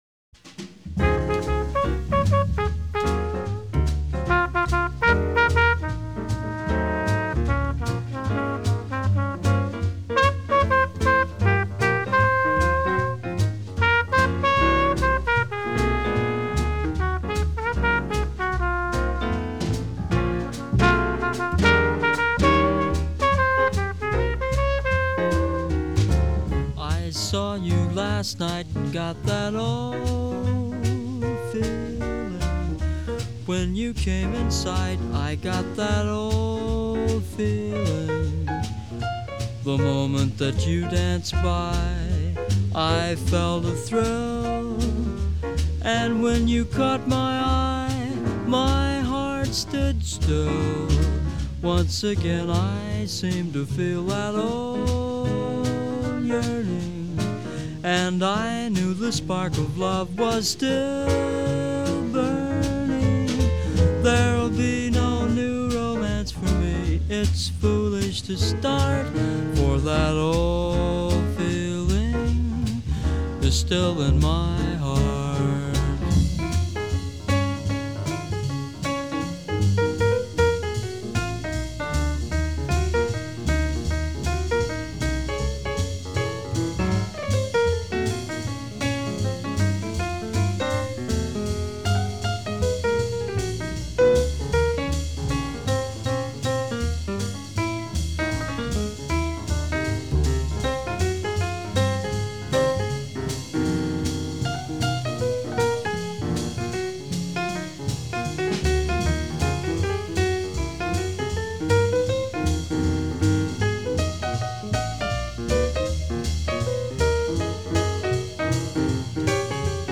Jazz, Cool Jazz